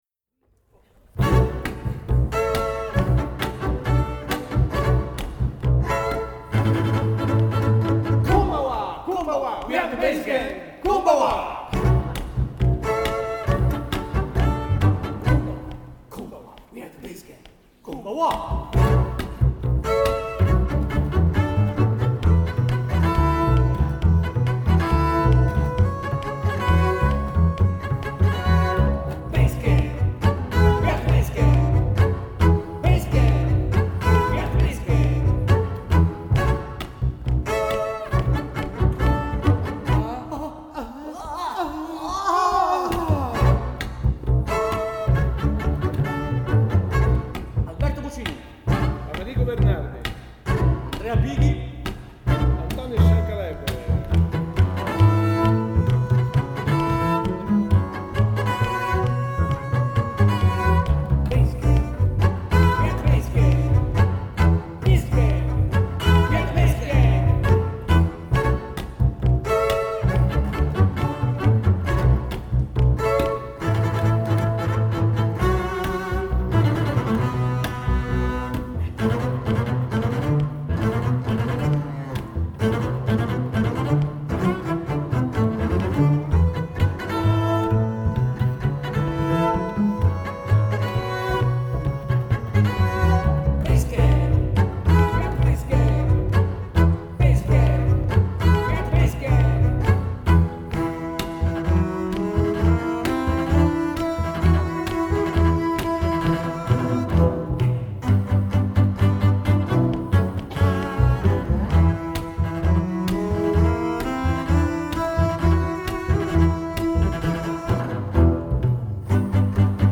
Live Recordings from the Concert at
WATANABE MEMORIAL HALL